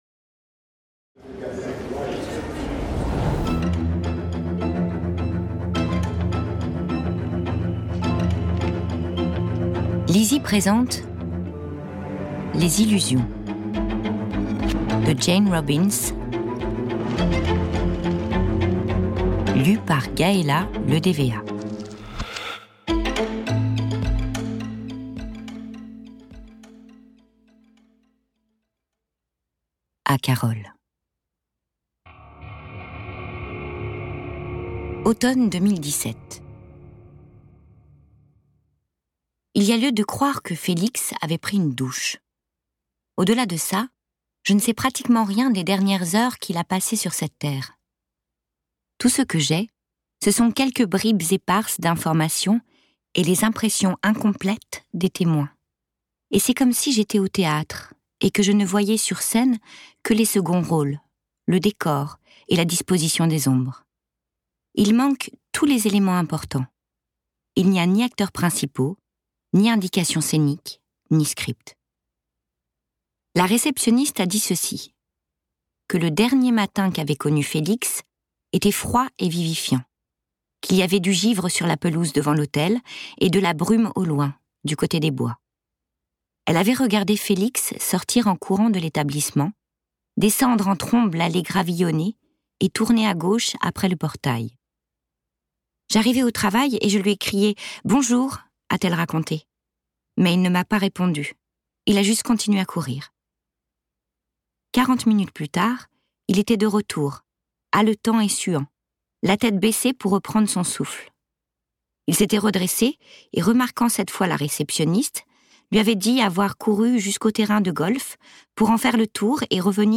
je découvre un extrait - Les Illusions de Jane ROBINS